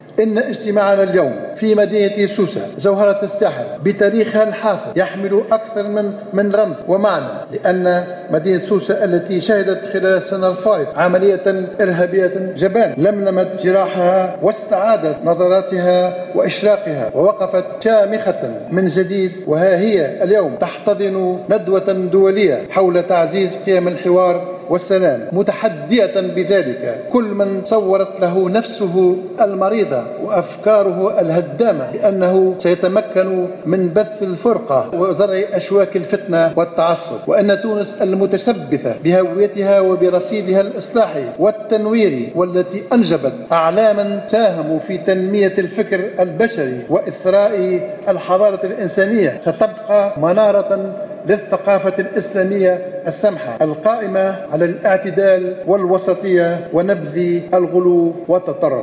أكد رئيس الحكومة الحبيب الصيد خلال افتتاحه اليوم الاثنين الندوة الدولية حول"تعزيز قيم السلام والحوار" بسوسة، أن تونس ستبقى منارة للثقافة الاسلامية القائمة على الاعتدال ونبذ الغلو والتطرف.